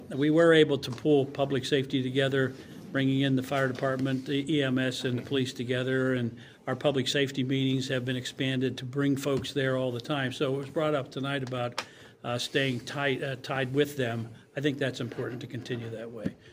Simmons, who has also served in the Indiana Fire Association and on borough council, said that he was very proud of the work that he was able to do.
12-2-25-bill-simmons.mp3